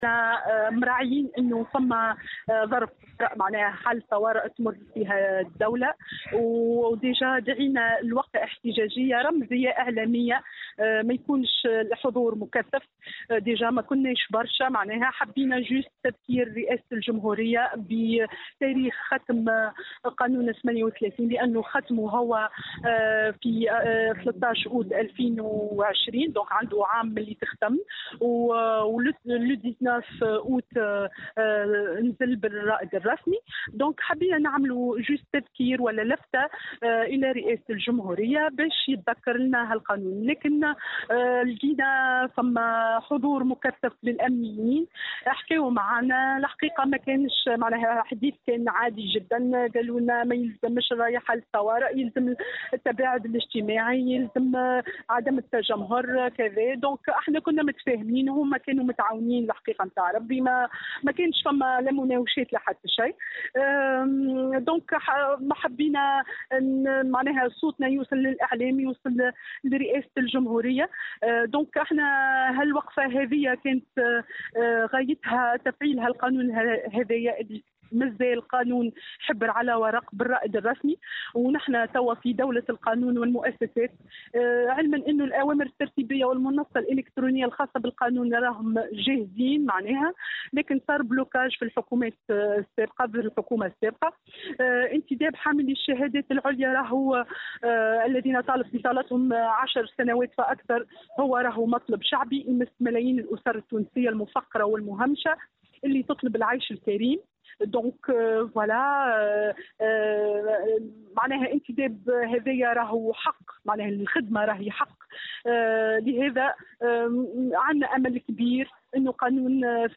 نظّم عدد من أصحاب الشهائد المعطلين عن العمل، اليوم الجمعة، وقفة احتجاجية رمزية، أمام المسرح البلدي بتونس العاصمة، للمطالبة بتفعيل القانون عدد 38 بعد مرور عام من المصادقة عليه من اجل الانتداب في الوظيفة العمومية.